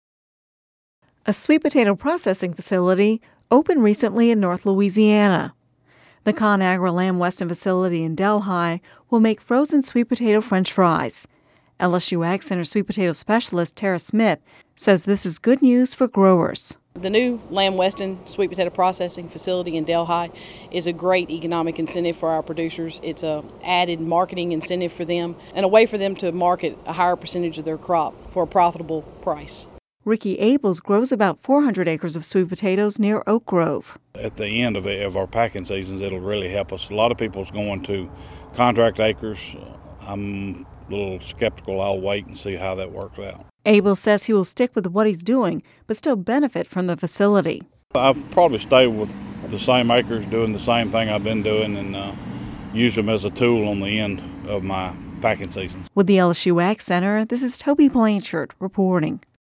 (Radio News 11/22/10) A sweet potato processing facility opened recently in north Louisiana. The ConAgra Lamb Weston facility in Delhi will make frozen sweet potato French fries.